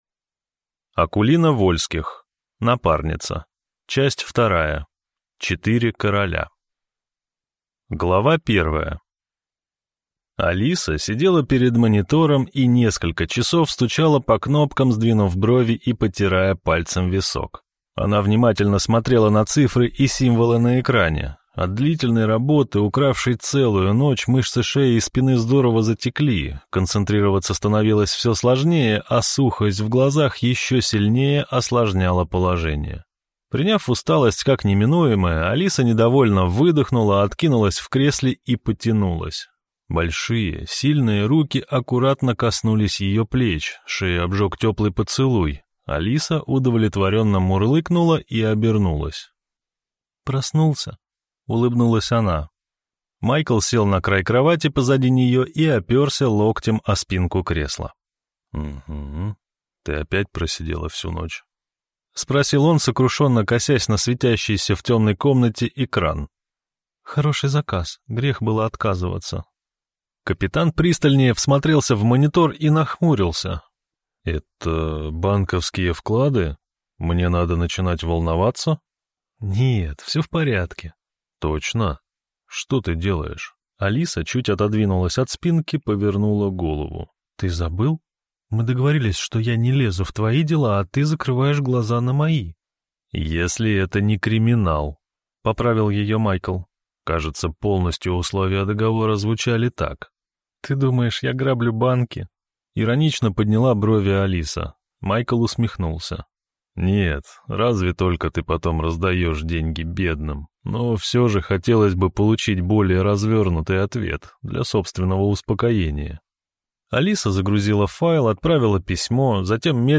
Аудиокнига Напарница. Часть 2. «Четыре короля» | Библиотека аудиокниг